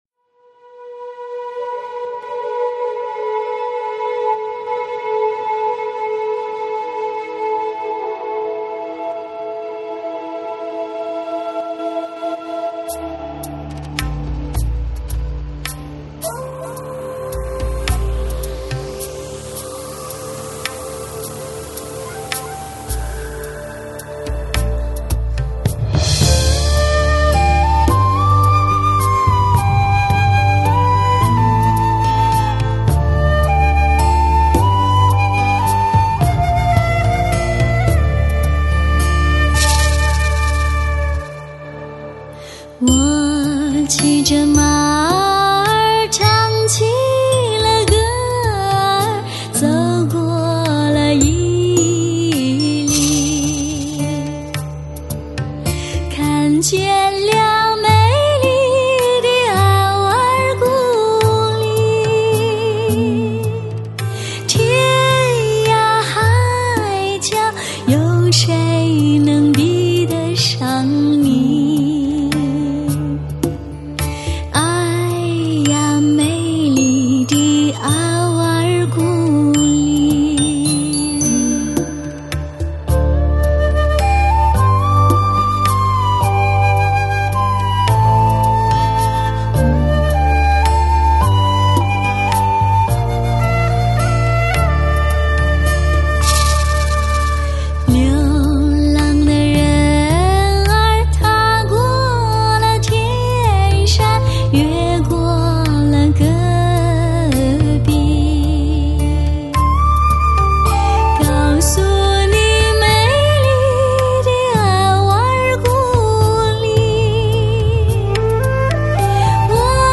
很好听的歌，可惜在3'09"处有爆音。
是这首歌，SRS环绕音效